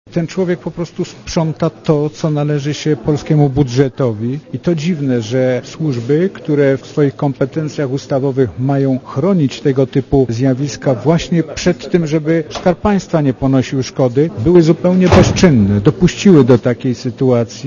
Mówi członek sejmowej komisji ds. służb specjalnych Zbigniew Wasserman